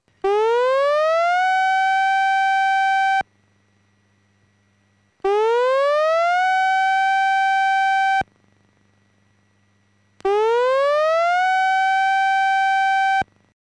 その後、大津波警報のサイレンが鳴りますので、プラスワン行動（下の例を参考）を行って
屋外スピーカー
大津波警報のサイレン音はこちら